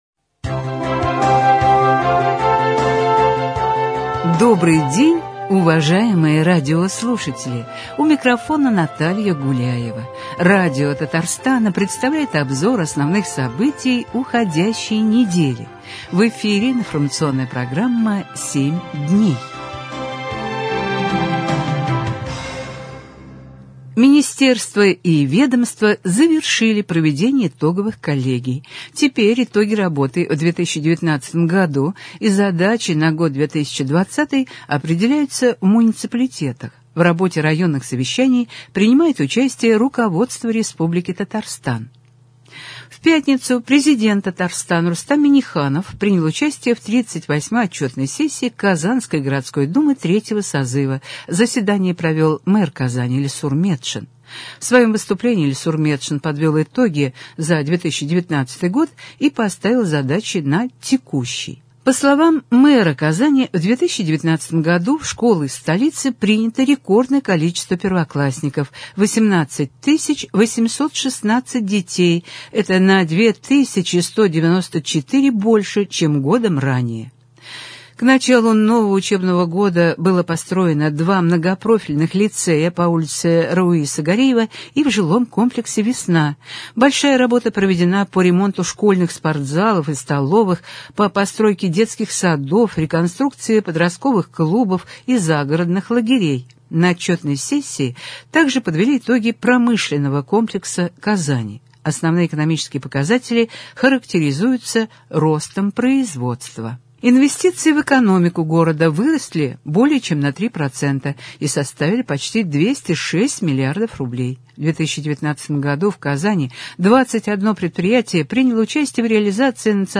Обзор событий.